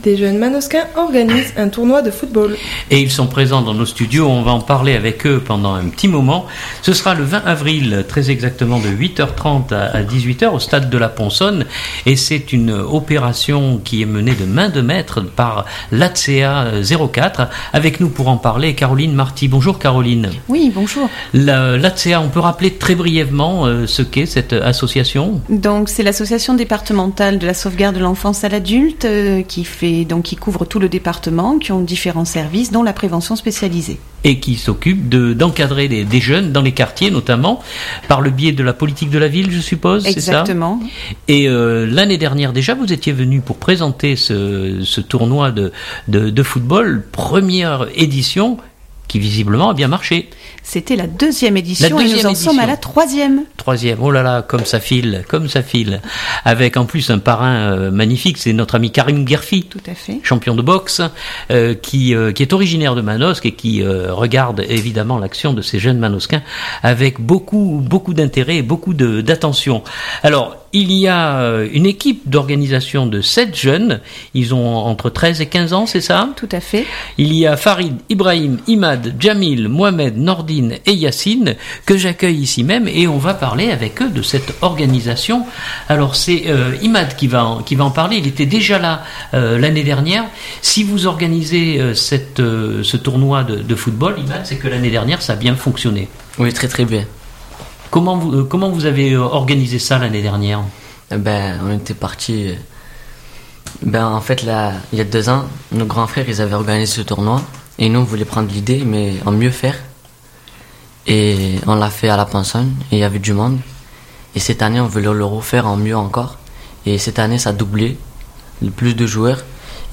Ils présentent leur manifestation et tirent au sort les rencontres de football à 7.